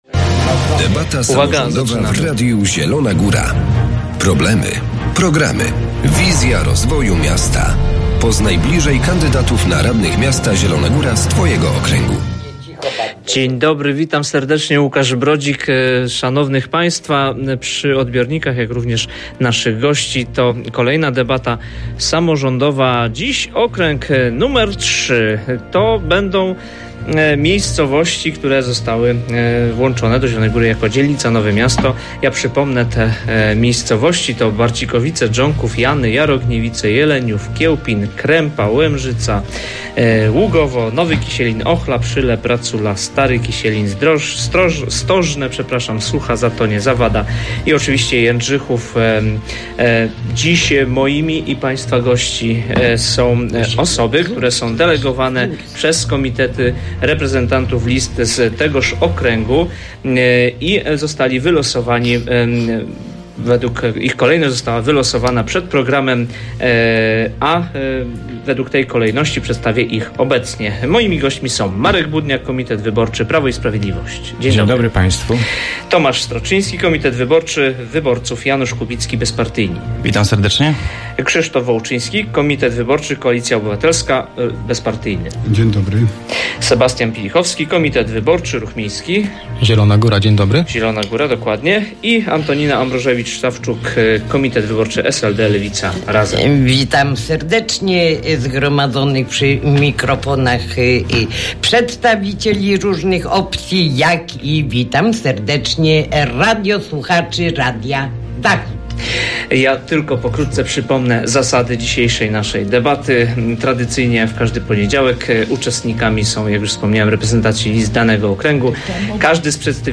Debata kandydatów na radnych – okręg nr 3
Debata samorządowa Radia Zielona Góra z udziałem przedstawicieli komitetów wystawiających swoich kandydatów do rady miasta z okręgu wyborczego nr 3.
Debata wyborcza Radia Zielona Góra